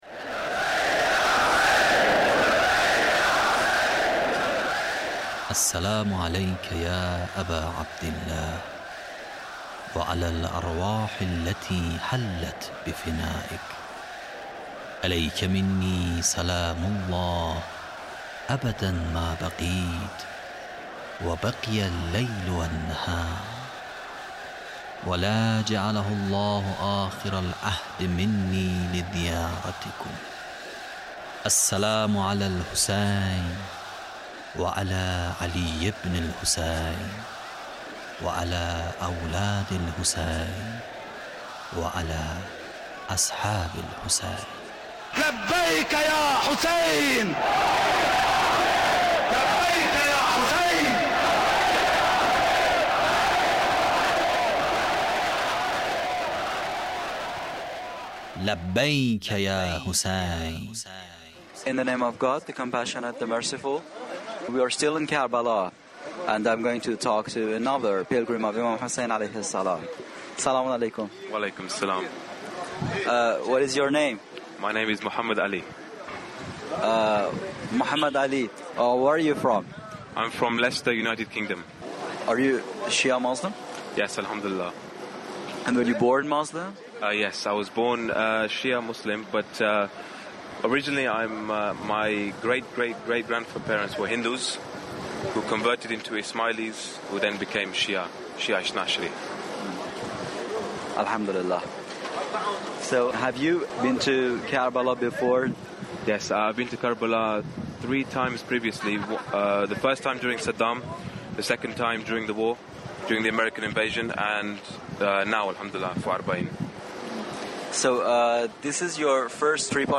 Interview with pilgrim of imam hussain (PART4)